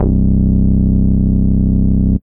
07SYN.BASS.wav